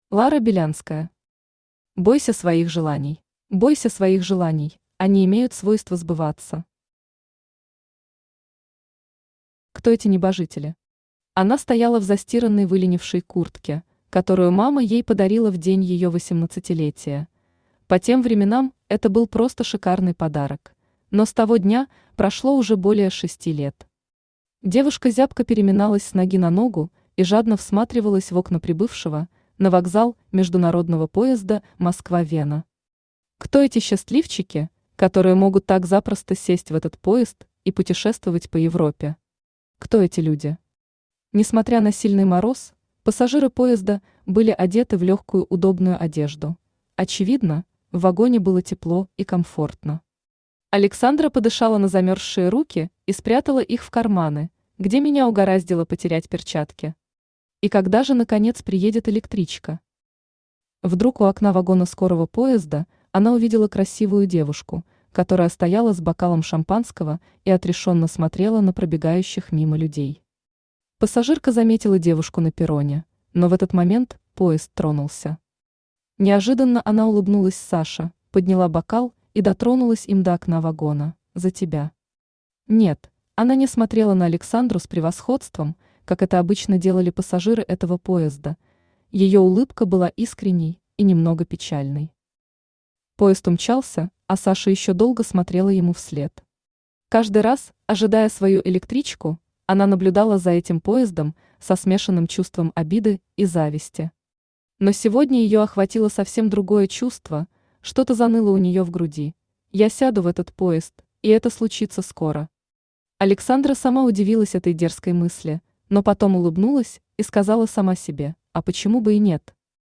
Аудиокнига Бойся своих желаний | Библиотека аудиокниг
Aудиокнига Бойся своих желаний Автор Лара Белянская Читает аудиокнигу Авточтец ЛитРес.